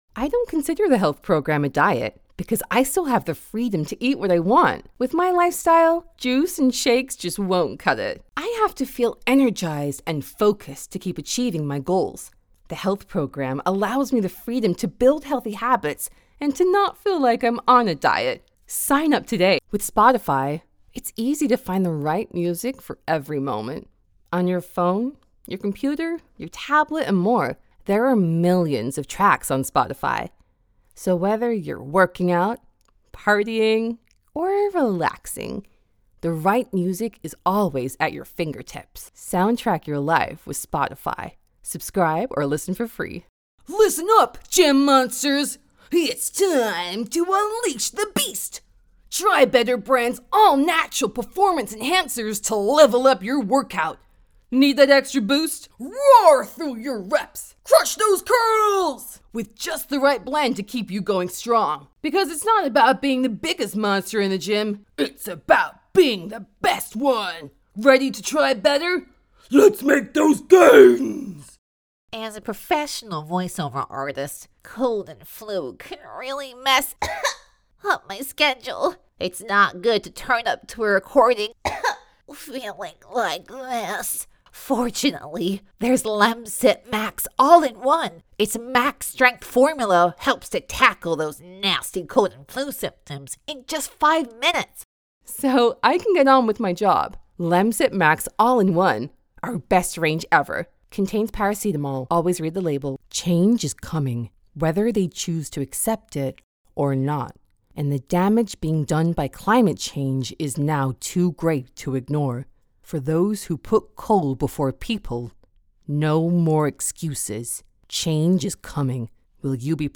Female
Adult (30-50)
Radio Commercials
Standard American Commercial
0115Standard_American_Voice_Reel.mp3